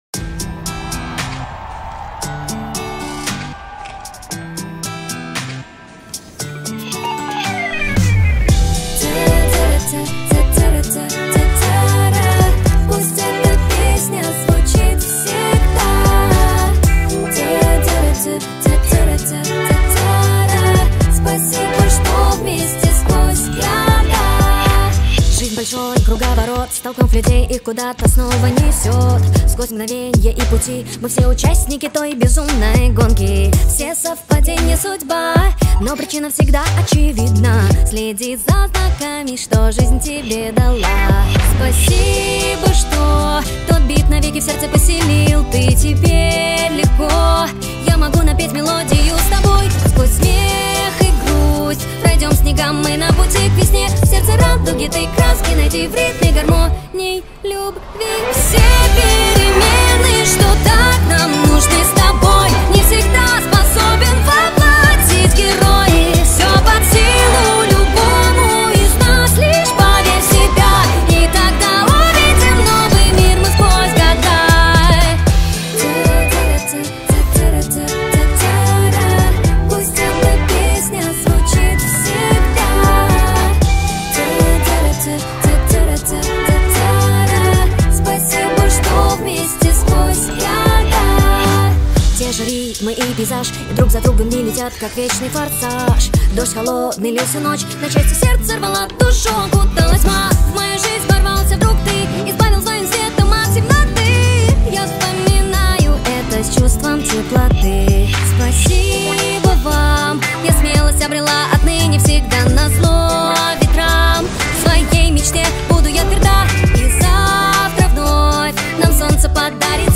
• Жанр: Новые каверы